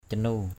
/ca-nu:/ 1.